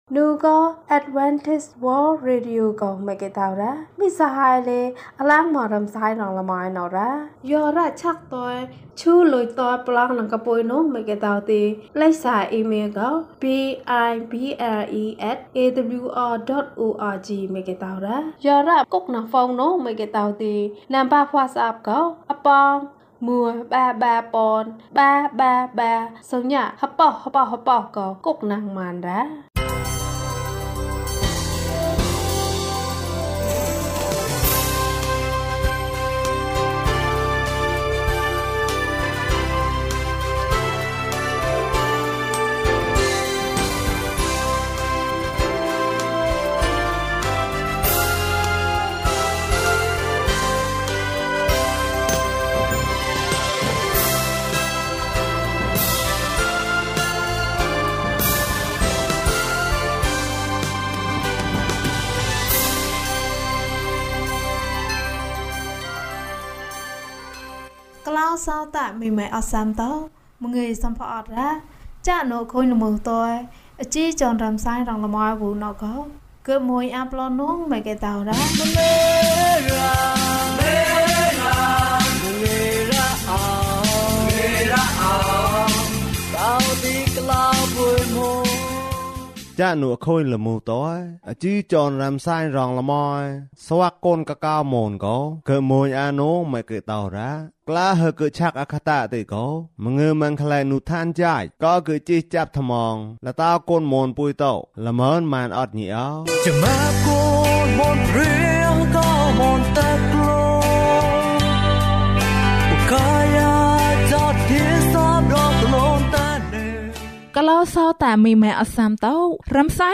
ကျွန်တော်သွားမည်။ အပိုင်း (၁) ကျန်းမာခြင်းအကြောင်းအရာ။ ဓမ္မသီချင်း။ တရားဒေသနာ။